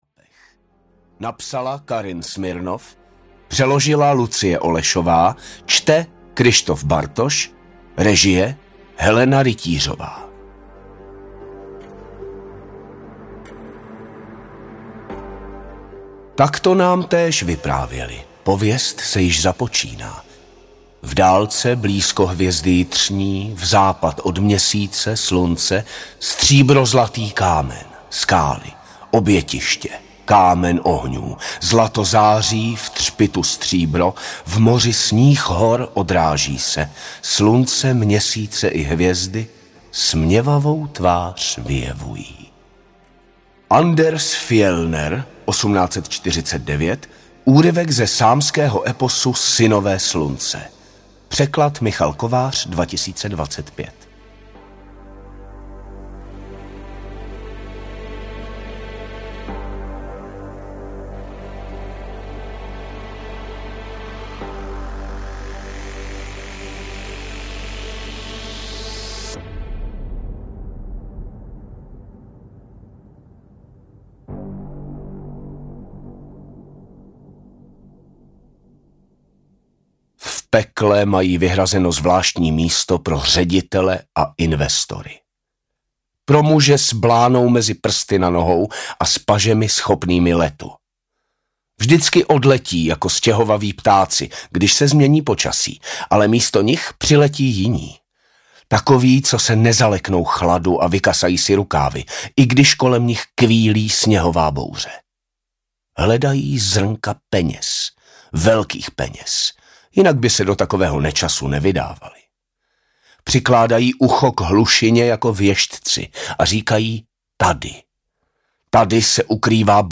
Dívka v rysích drápech audiokniha
Ukázka z knihy